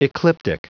Prononciation du mot ecliptic en anglais (fichier audio)
Prononciation du mot : ecliptic